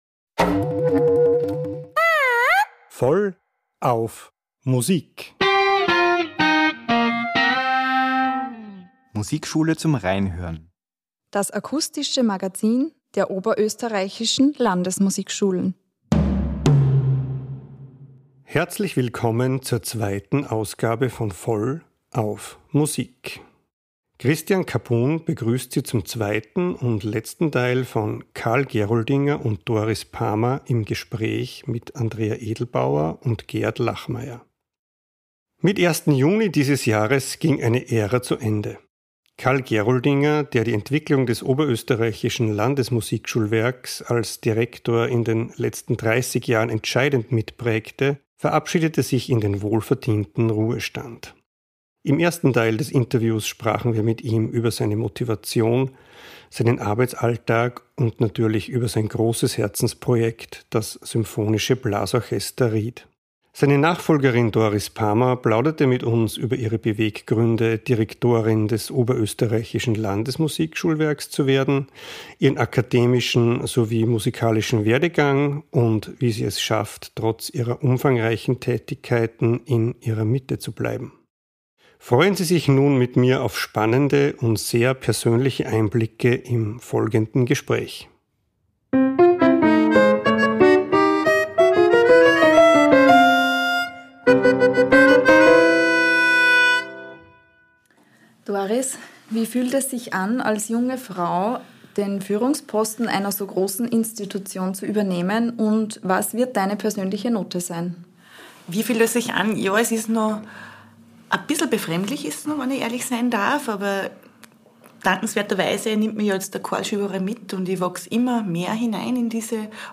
Fragen wie diese untermalt mit bewegender Musik werden im zweiten Teil der Doppelfolge „Staffelübergabe“ beantwortet.